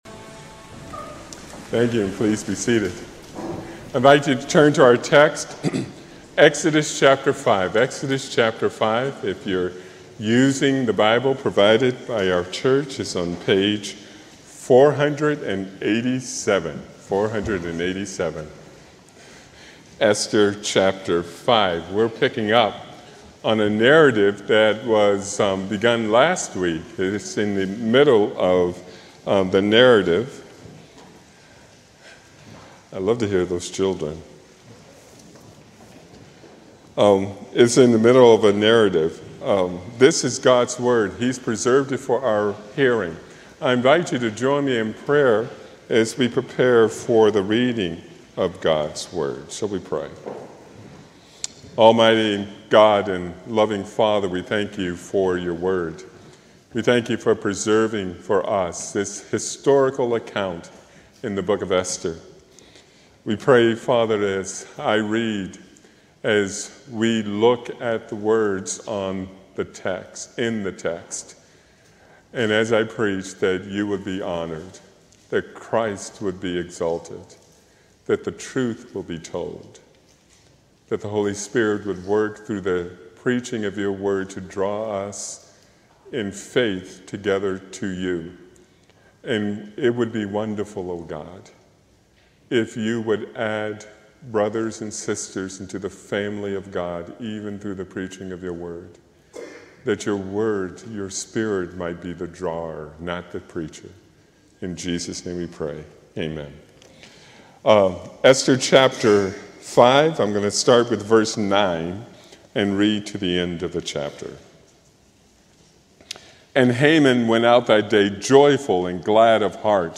A sermon from the series "Esther." Esther 6:5-7:10 November 2, 2025 Morning